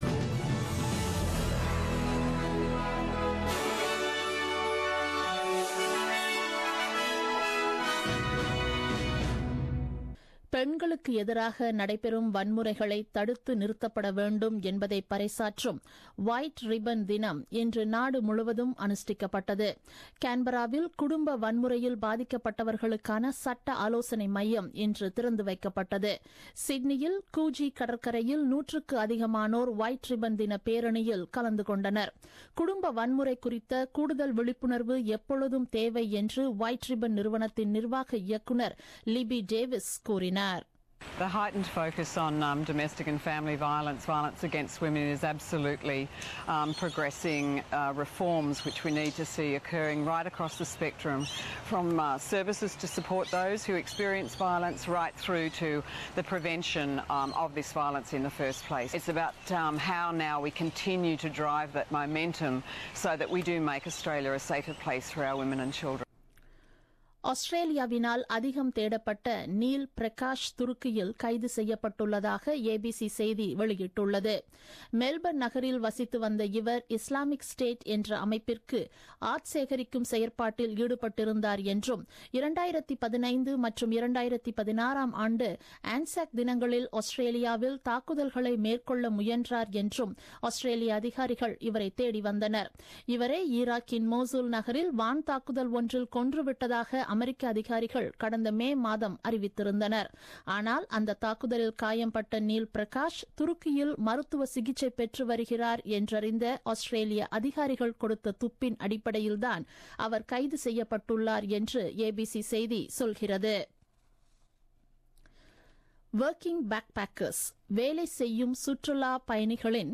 The news bulletin broadcasted on 18 Nov 2016 at 8pm.